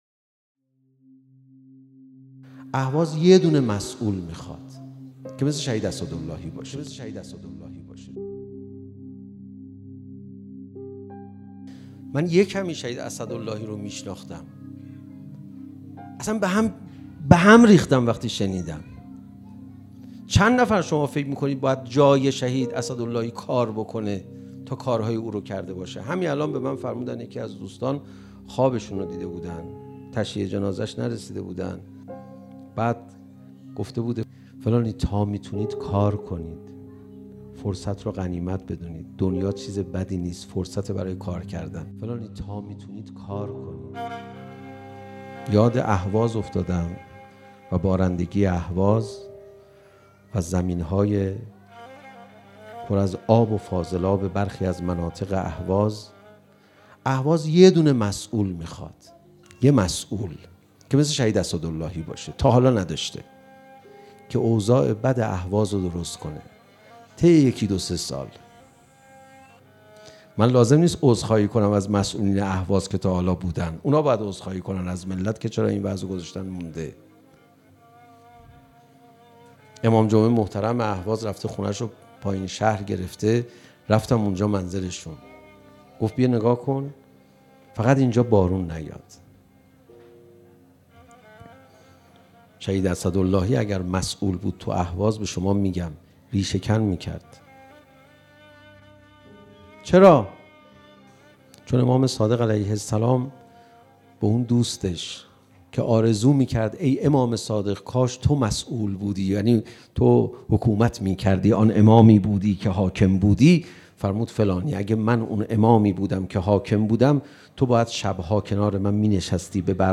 شناسنامه تولید: بیان معنوی مدت زمان: 2:43 منبع: چهارمین سالگرد شهید اسدللهی دریافت با کیفیت (پایین(5مگابایت) | متوسط(12مگابایت) | بالا (33مگابایت) | صوت ) آپارات متن: اهواز یک دانه مسئول می‌خواهد که مثل شهید اسداللهی باشد.